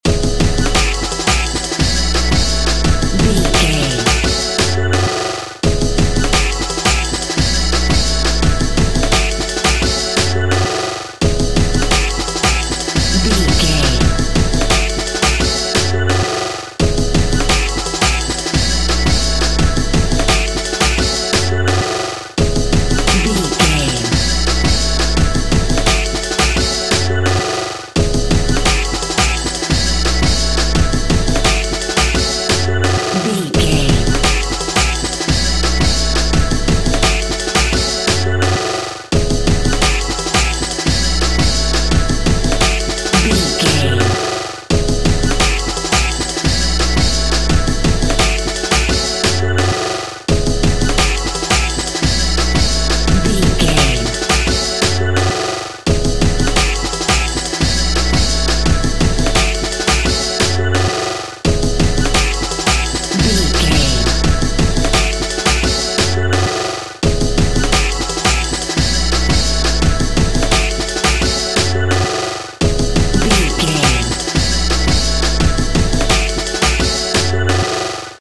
Drum n Bass Music.
Ionian/Major
Fast
groovy
uplifting
futuristic
driving
energetic
repetitive
drum machine
synthesiser
break beat
electronic
sub bass
synth lead
synth bass